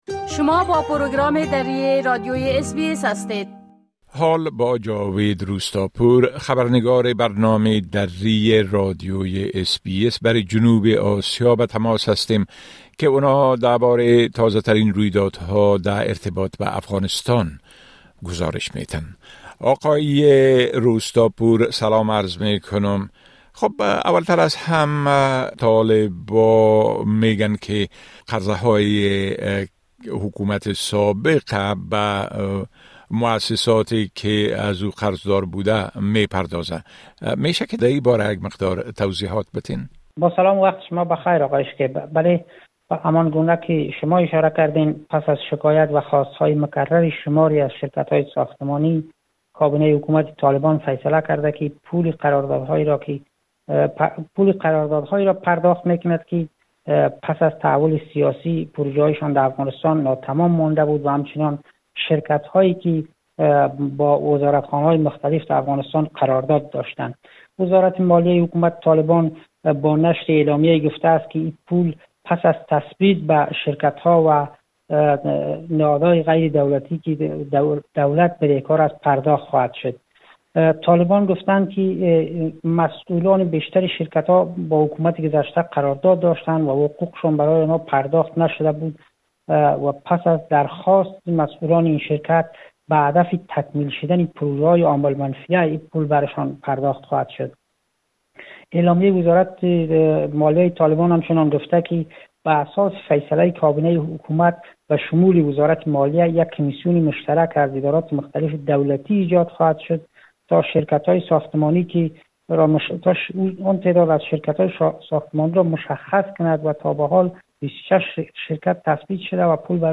گزارش كامل خبرنگار ما، به شمول اوضاع امنيتى و تحولات مهم ديگر در افغانستان را در اينجا شنيده مى توانيد.